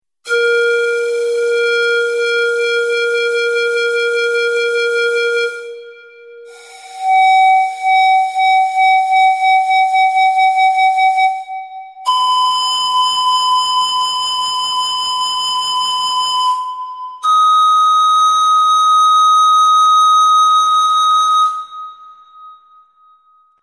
Poniżej zamieszczono przykładowe dźwięki otrzymane przy pomocy modelu fizycznego.
efekt wibrato